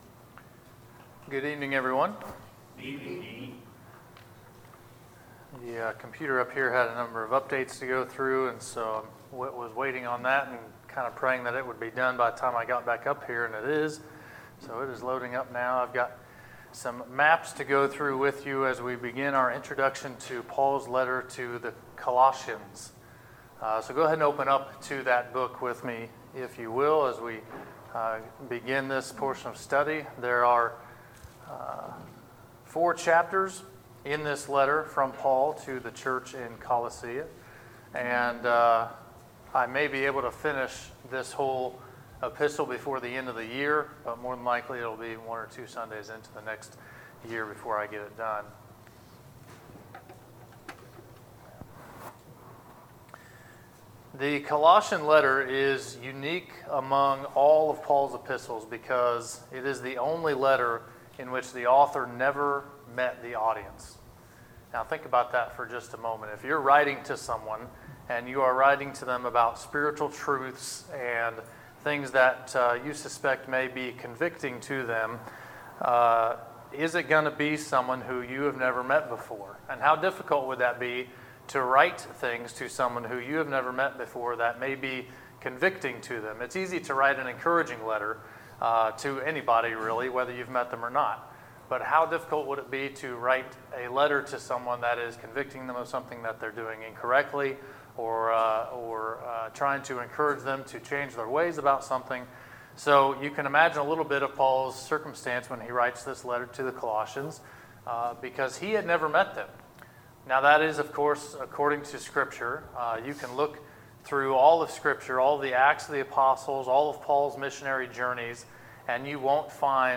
Sermons, September 8, 2019